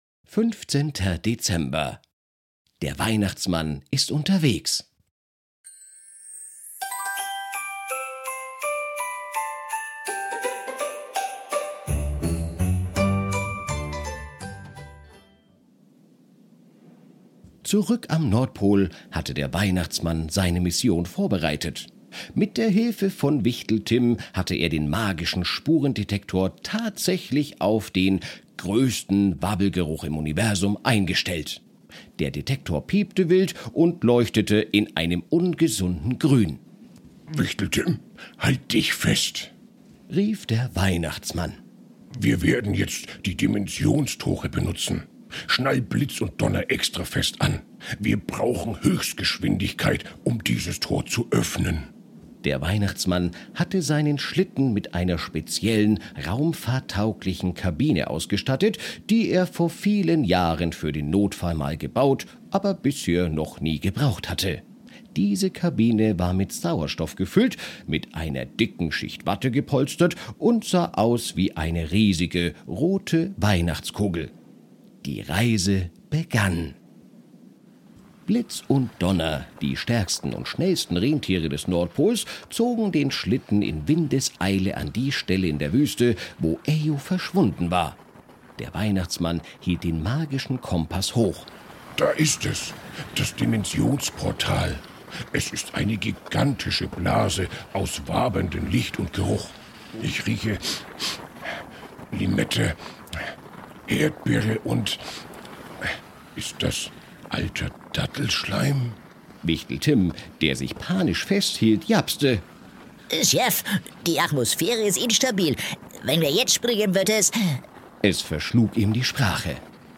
Ein Kinder Hörspiel Adventskalender